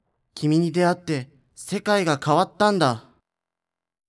ボイス
パワフル男性